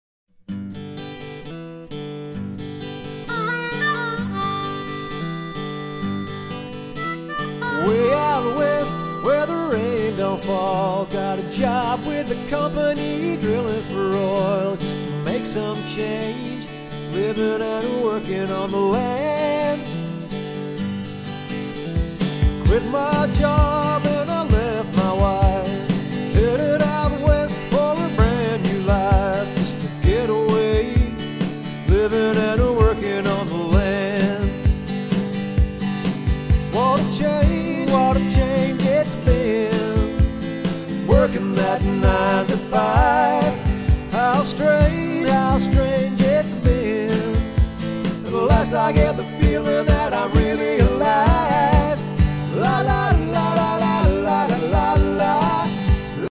Classic songs from the Land Down Under